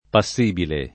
[ pa SS& bile ]